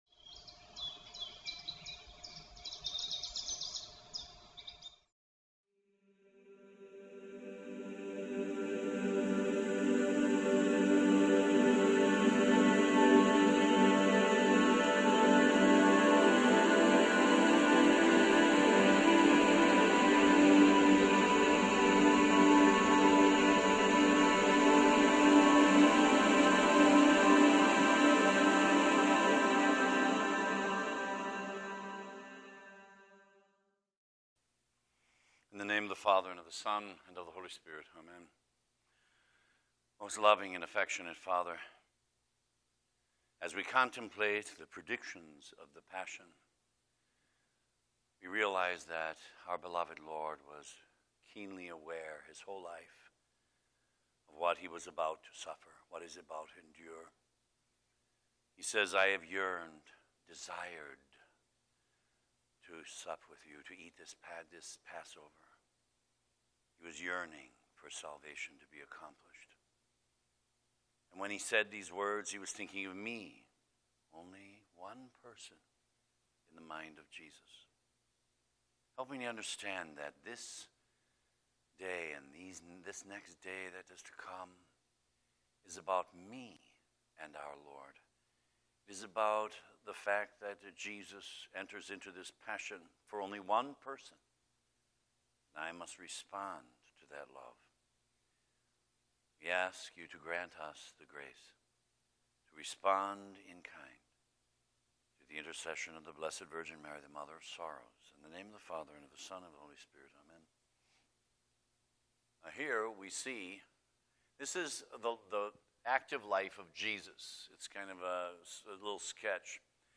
8 Day Retreat